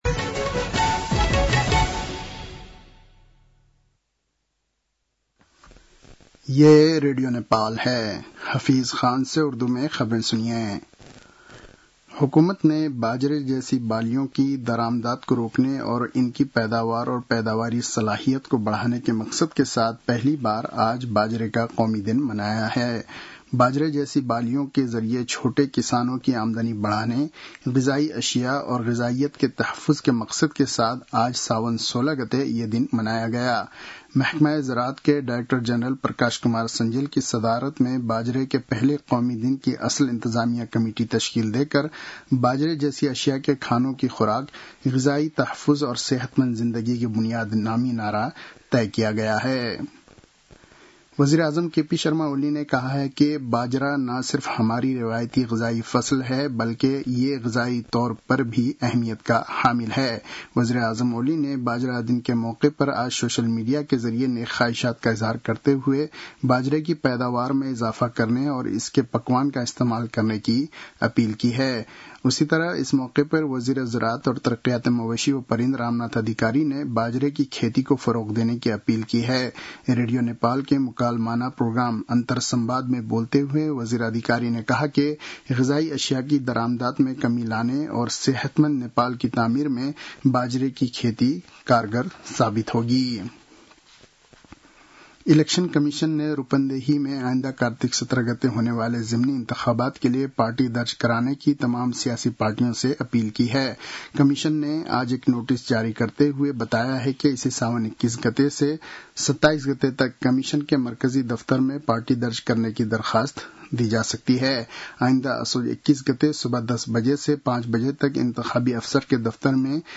उर्दु भाषामा समाचार : १६ साउन , २०८२
Urdu-news-4-16.mp3